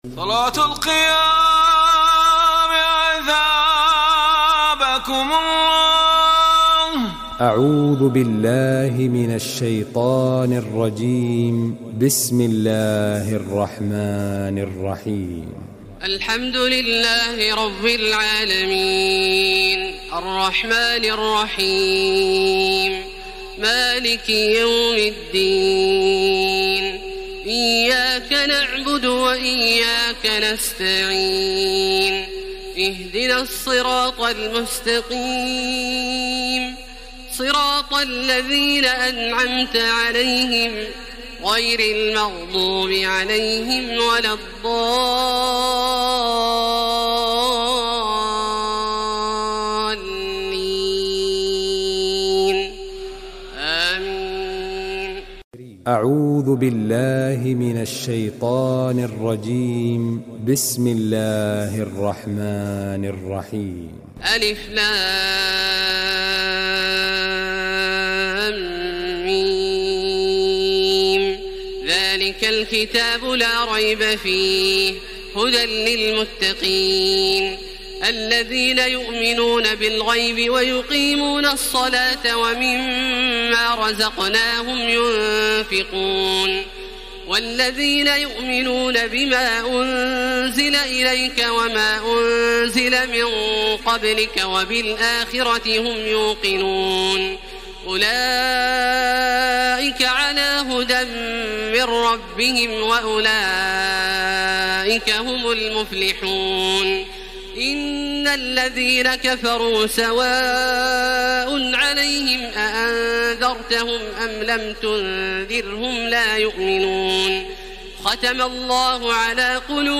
تراويح الليلة الأولى رمضان 1433هـ من سورة البقرة (1-86) Taraweeh 1st night Ramadan 1433 H from Surah Al-Baqara > تراويح الحرم المكي عام 1433 🕋 > التراويح - تلاوات الحرمين